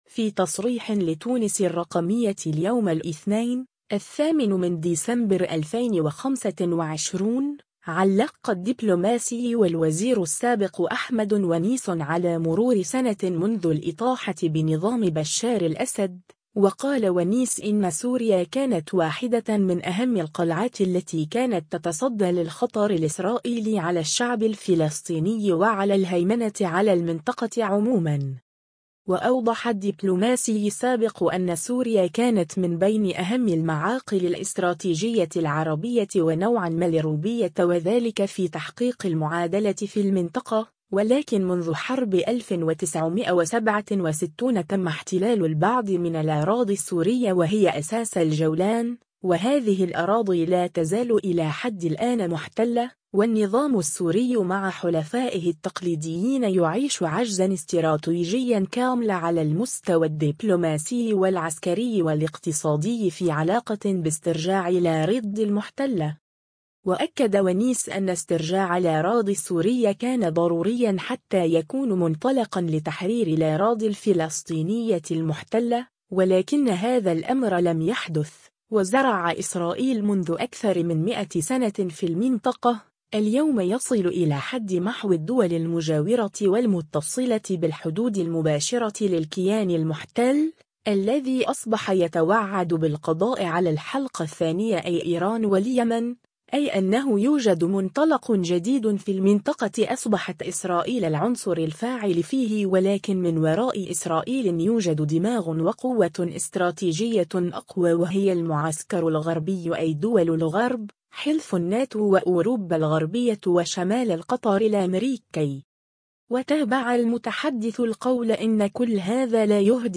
في تصريح لتونس الرّقمية اليوم الإثنين، 08 ديسمبر 2025، علقّ الدّبلوماسي و الوزير السابق أحمد ونيس على مرور سنة منذ الإطاحة بنظام بشّار الأسد، و قال ونيس إنّ سوريا كانت واحدة من أهمّ القلعات التي كانت تتصدى للخطر الاسرائيلي على الشّعب الفلسطيني و على الهيمنة على المنطقة عموما.